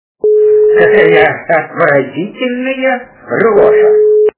» Звуки » Из фильмов и телепередач » Джентельмены удачи - Какая отвратительная рожа
При прослушивании Джентельмены удачи - Какая отвратительная рожа качество понижено и присутствуют гудки.